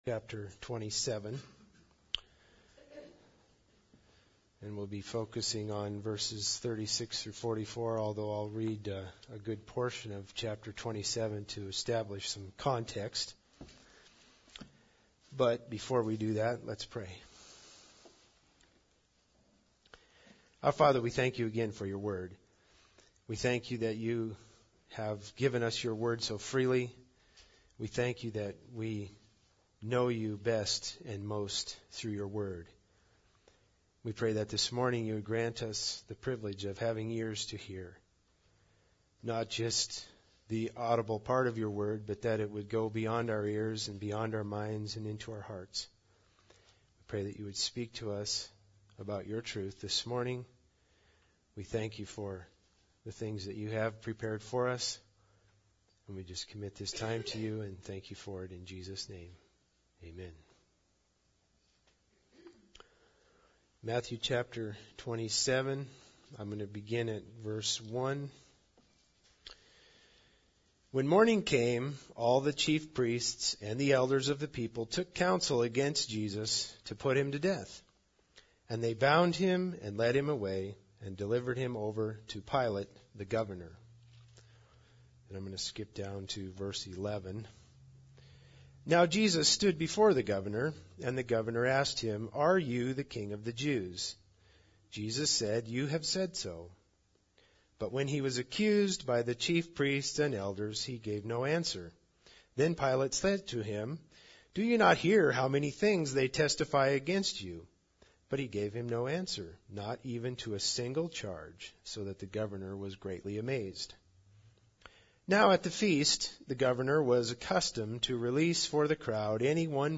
Matthew 27:36-44 Service Type: Sunday Service Bible Text